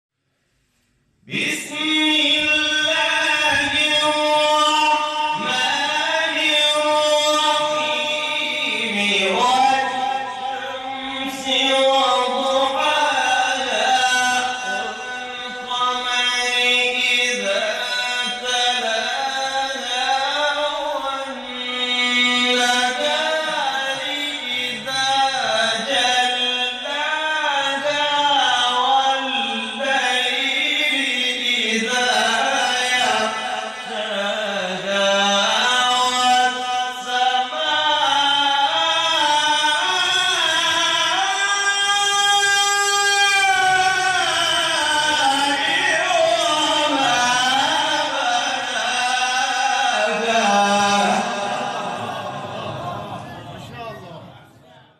گروه شبکه اجتماعی: جدیدترین مقاطع صوتی از تلاوت قاریان بنام و ممتاز کشور را که به تازگی در شبکه‌های اجتماعی منتشر شده است، می‌شنوید.
سوره مبارکه شمس اجرا شده در مقام سگاه